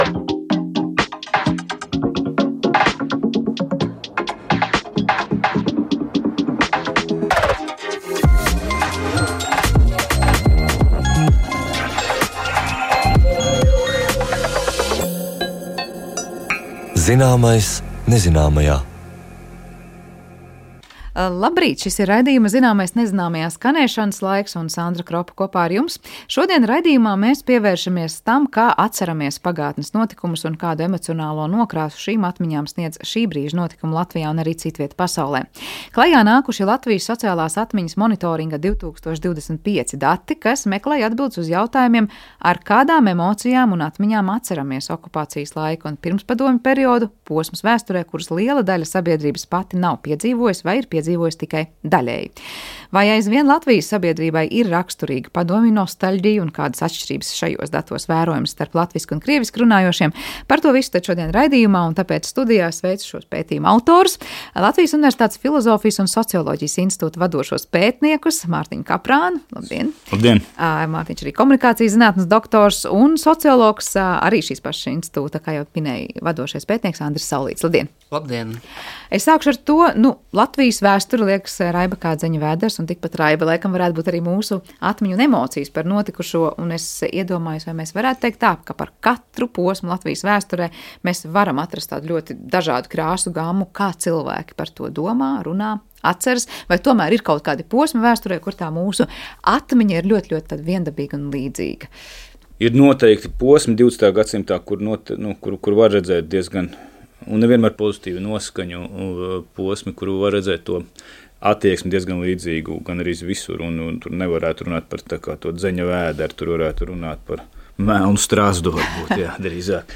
Raidījumā Zināmais nezināmajā saruna par ļoti neparastiem eksperimentiem uz mūsu planētas.